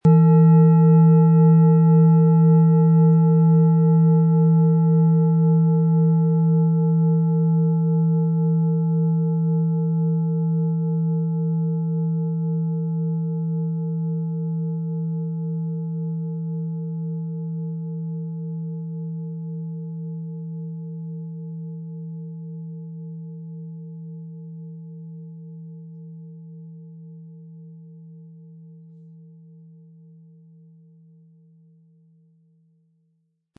Handgetriebene, tibetanische Planetenklangschale Hopi-Herzton.
Unter dem Artikel-Bild finden Sie den Original-Klang dieser Schale im Audio-Player - Jetzt reinhören.
Durch die traditionsreiche Fertigung hat die Schale vielmehr diesen kraftvollen Ton und das tiefe, innere Berühren der traditionellen Handarbeit
Mit einem sanften Anspiel "zaubern" Sie aus der Hopi-Herzton mit dem beigelegten Klöppel harmonische Töne.
PlanetentöneHopi Herzton & Mond
MaterialBronze